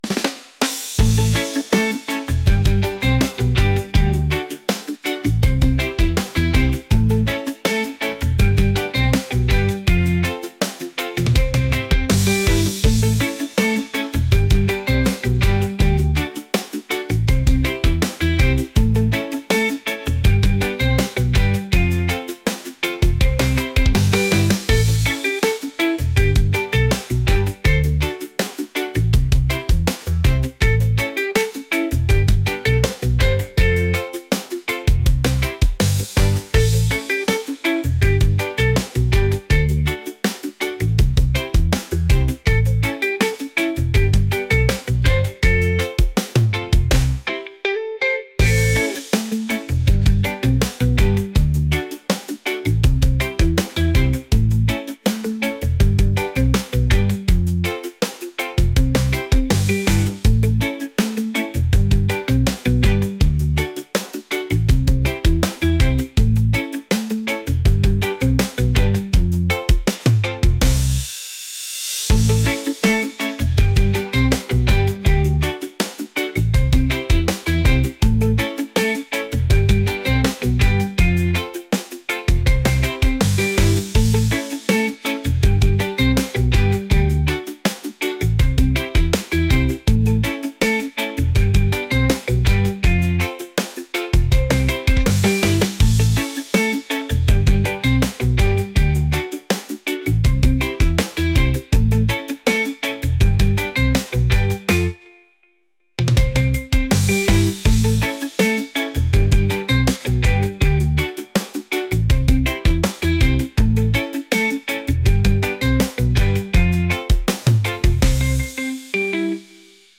reggae | rock | upbeat